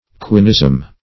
quinism - definition of quinism - synonyms, pronunciation, spelling from Free Dictionary
Quinism \Qui"nism\, n.
quinism.mp3